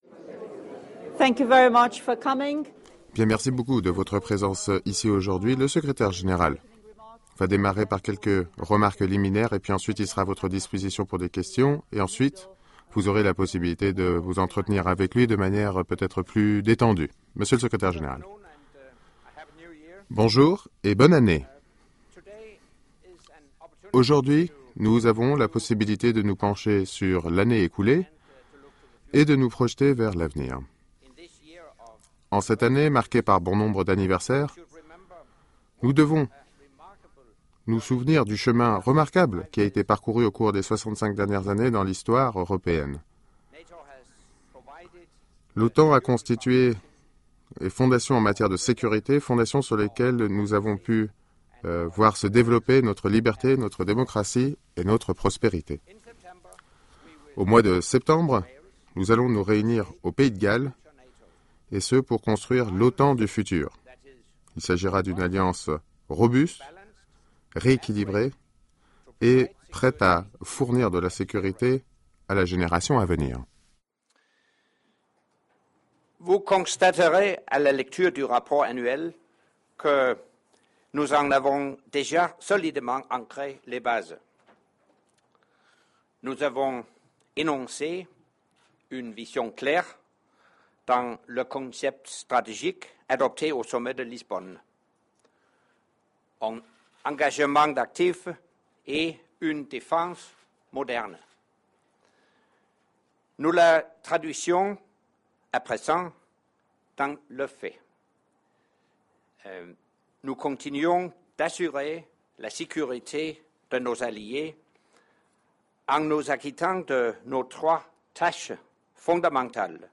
Opening remarks by NATO Secretary General Anders Fogh Rasmussen at the press conference on the occasion of the release of his Annual Report for 2013